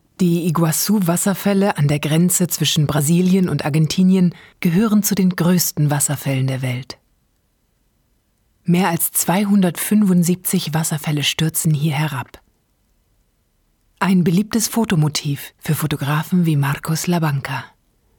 Deutscher Sprecher, Off-Sprecher, mittlere bis tiefe Stimme, Hörspiel, Hörbuch, Voice over, Audiodeskription (Little Dream Entertainment, ARD, arte)
norddeutsch
Sprechprobe: Industrie (Muttersprache):